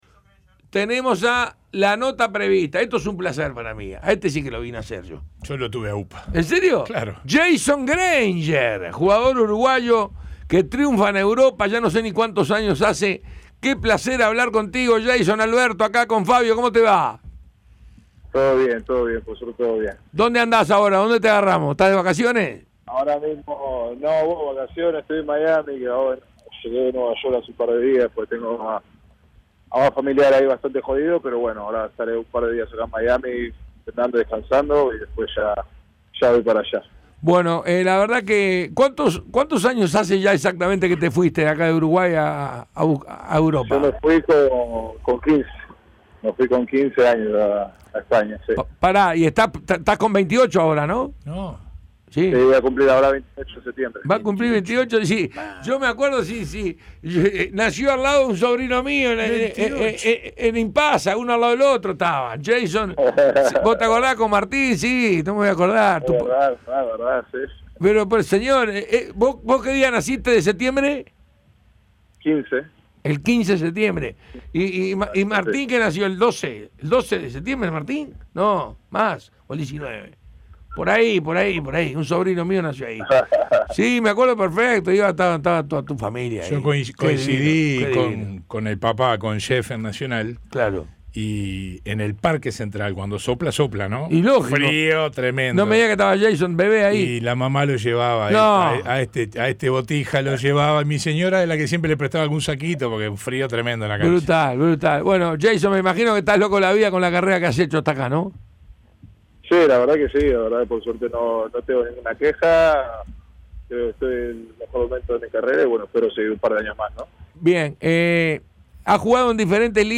Además, explicó su situación actual y expresó su deseo de jugar en la NBA. Entrevista completa e imperdible.